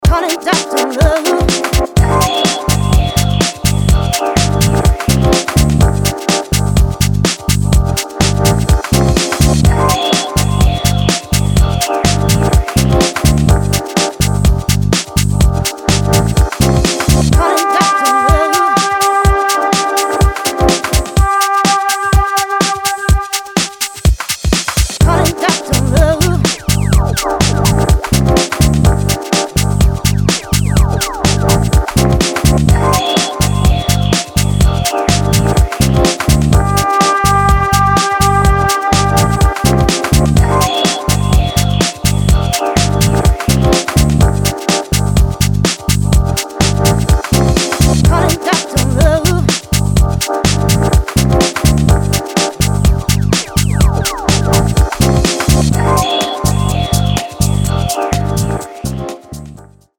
Lounge Session